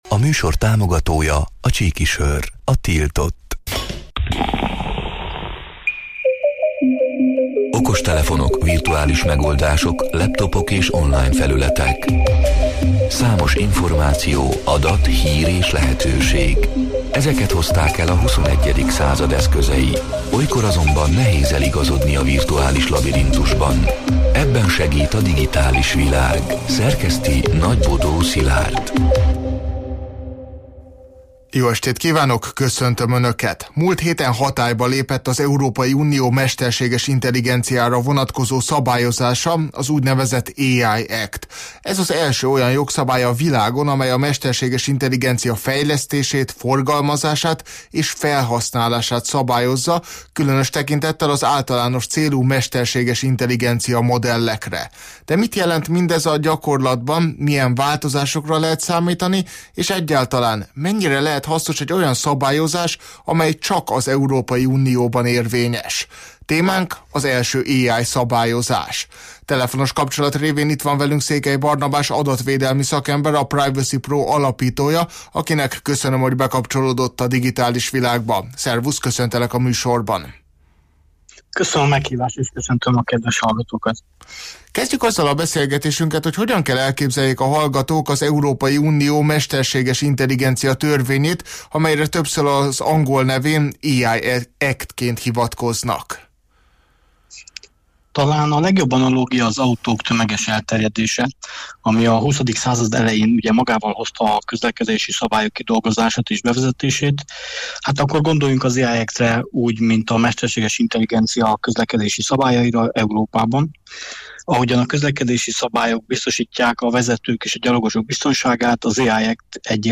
A Marosvásárhelyi Rádió Digitális Világ (elhangzott: 2025. augusztus 12-én, kedden este nyolc órától élőben) c. műsorának hanganyaga: